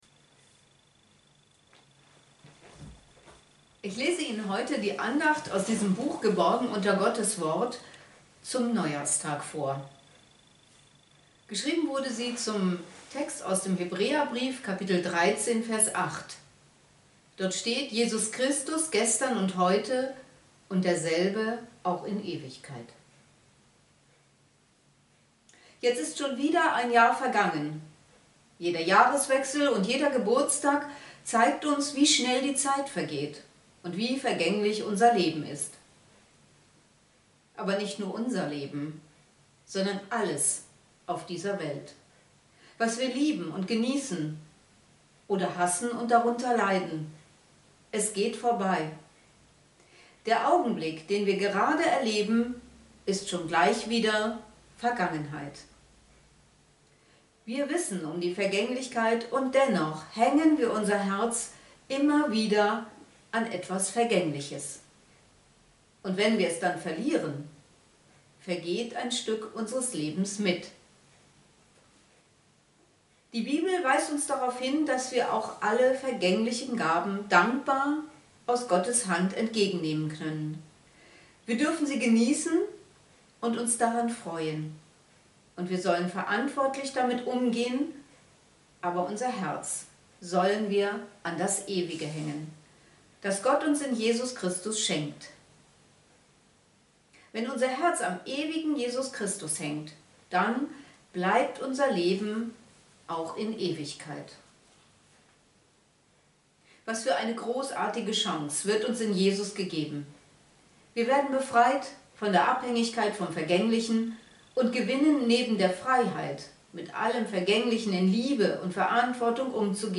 Andacht-zum-Neujahrstag.mp3